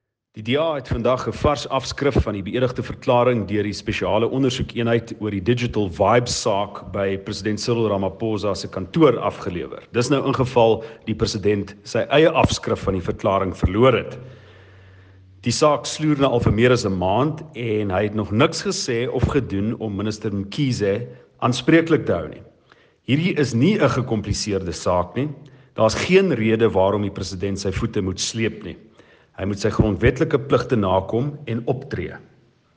Afrikaans soundbites by Cilliers Brink MP.